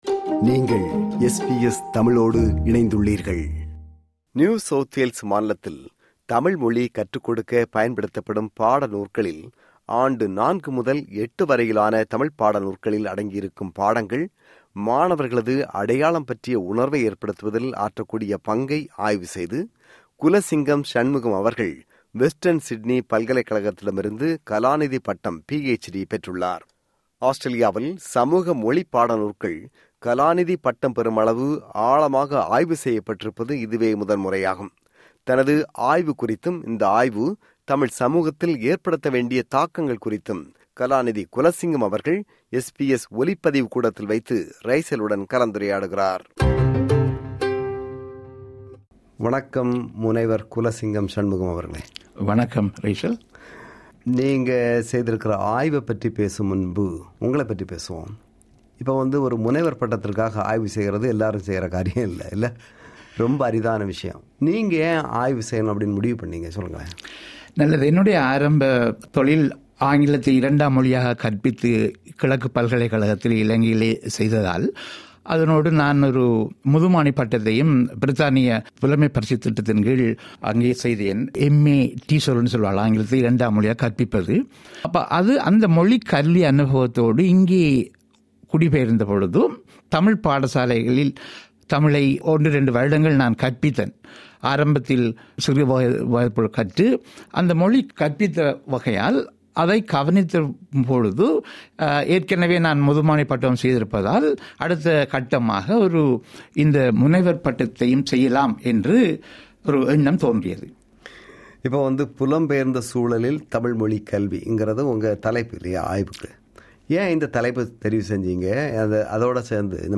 SBS ஒலிப்பதிவு கூடத்தில்